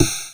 SNARE 18  -L.wav